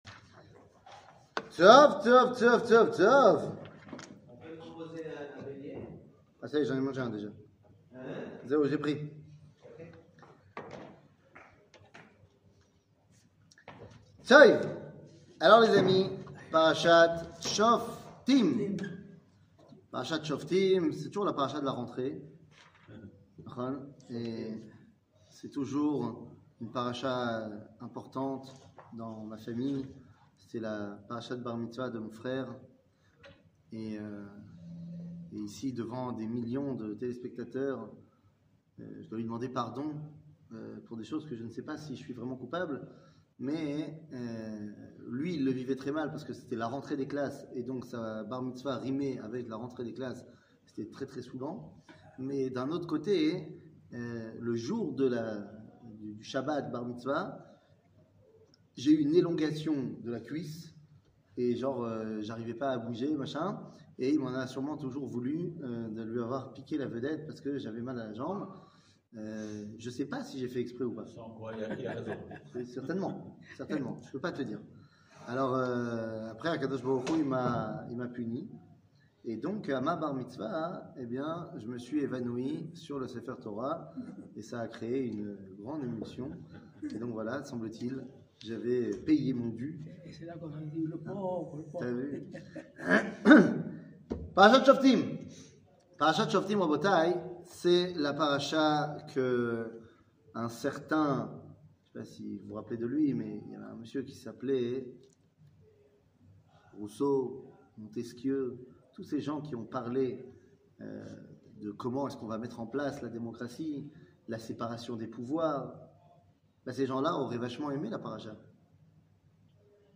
Parachat Choftim, La séparation des pouvoirs 00:29:42 Parachat Choftim, La séparation des pouvoirs שיעור מ 30 אוגוסט 2022 29MIN הורדה בקובץ אודיו MP3 (27.19 Mo) הורדה בקובץ וידאו MP4 (88.3 Mo) TAGS : שיעורים קצרים